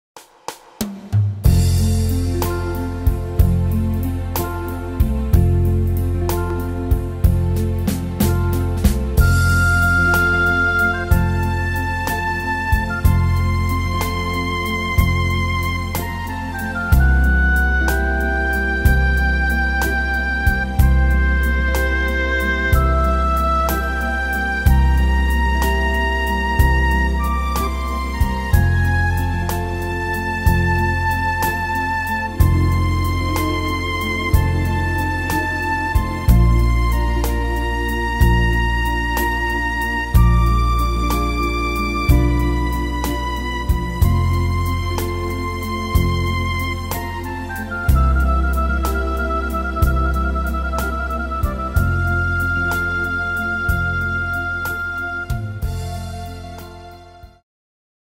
Tempo: 62 / Tonart: F-Dur